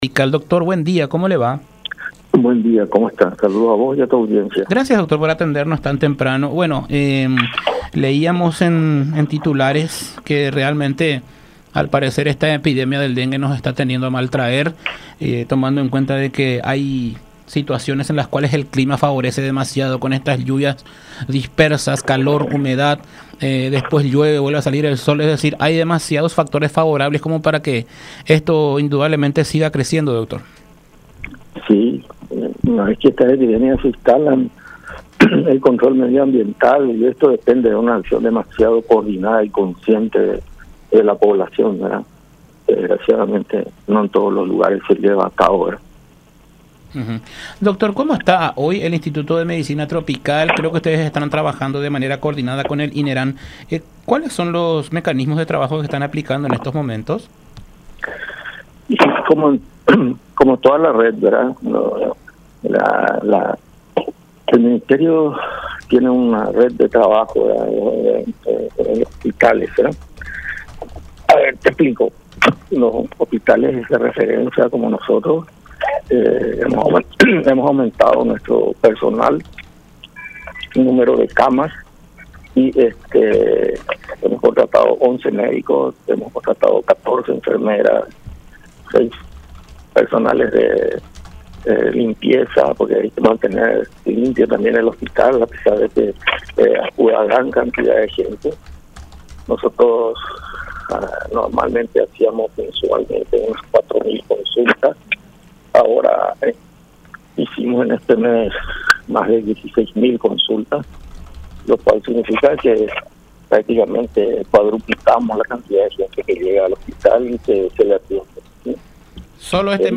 Entre enero pasado y los primeros días de febrero atendimos a aproximadamente 16.000 consultas”, expuso el profesional en diálogo con La Unión, señalando que este incremento significativo se debió al dengue.